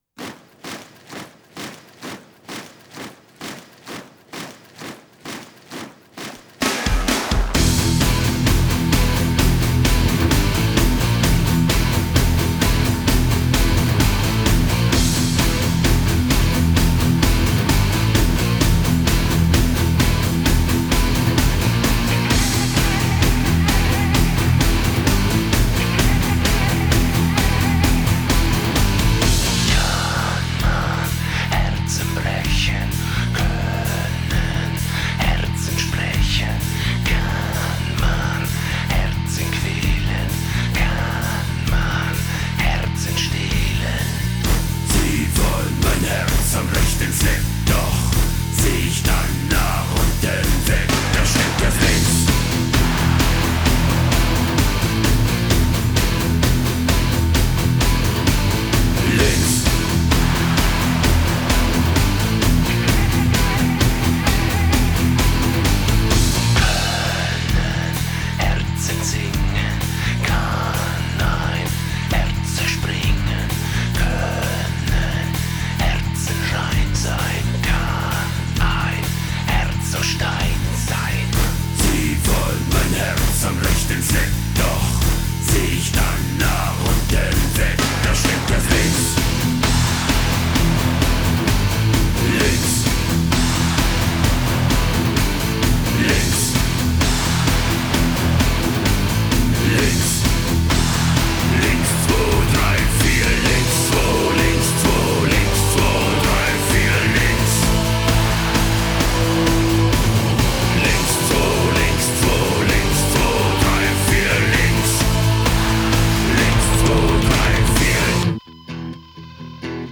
Файл в обменнике2 Myзыкa->Зарубежный рок
Жанры: Neue Deutsche Herte, индастриал-метал,
хард-рок, готик-метал